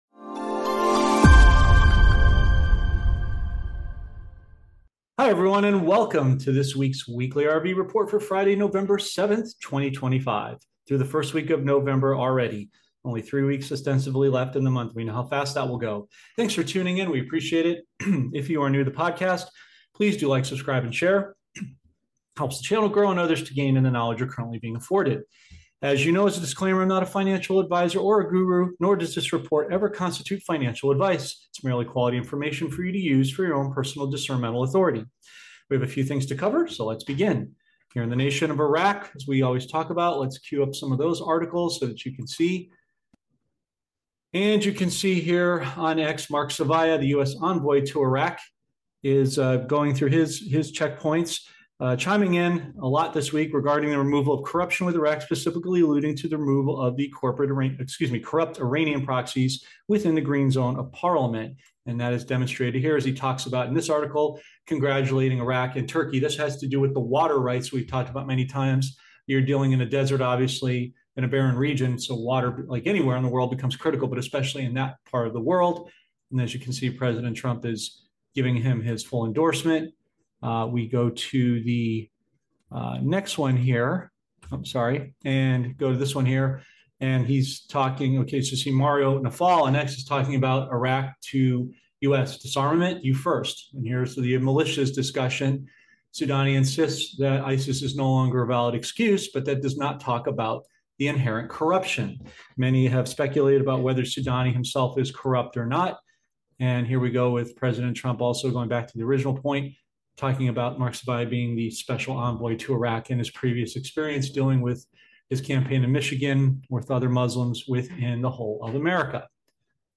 ➡ This week’s RV report discusses various global issues, including the U.S. envoy’s efforts to eliminate corruption in Iraq, the upcoming elections in Iraq, and the potential for a new prime minister. It also mentions the ongoing crisis in Southeast Asia due to a hurricane, with a call for prayers and support. The report also covers the impact of dropping oil prices on the economy, President Trump’s backup plan regarding tariff collections, and the current trading values of precious metals.